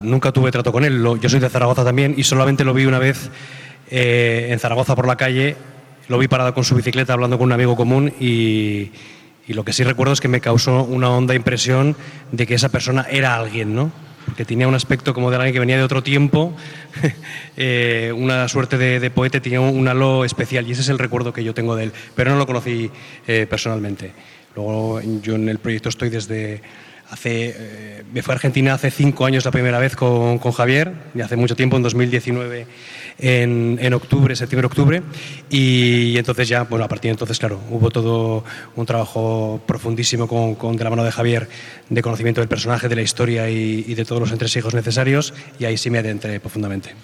22-11_fical_estrella_azul_actor.mp3